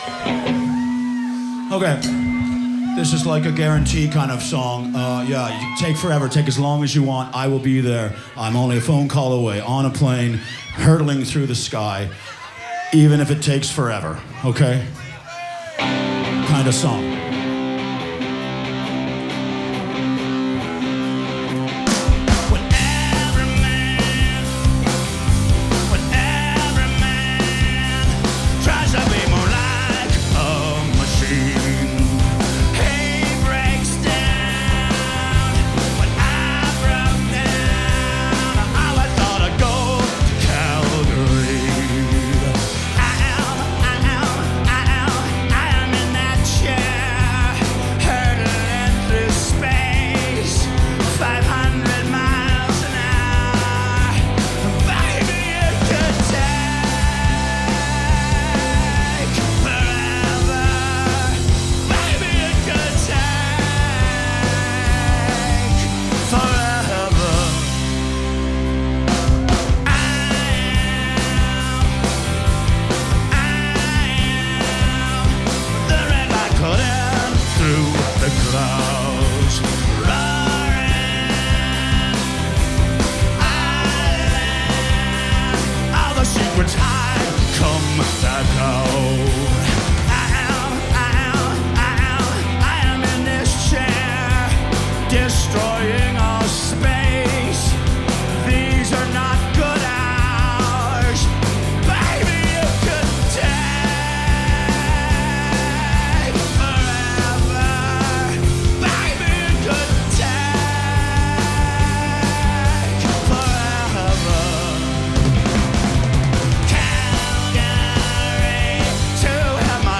(2nd Live Performance)